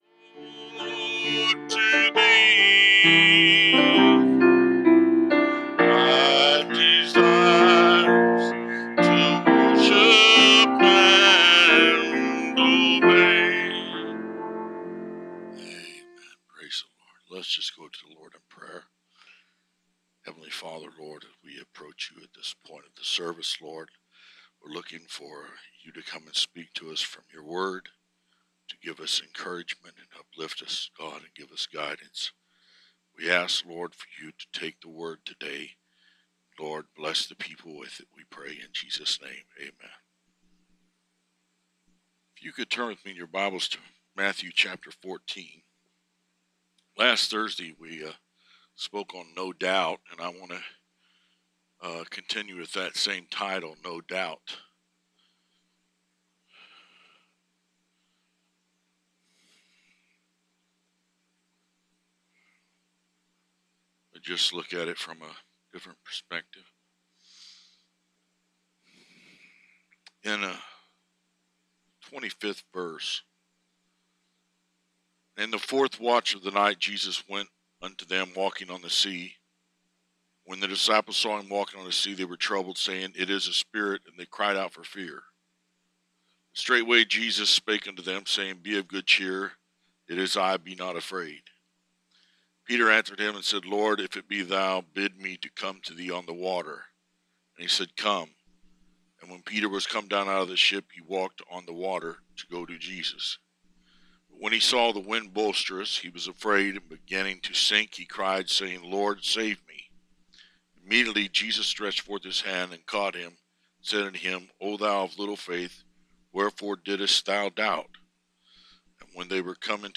Audio Preached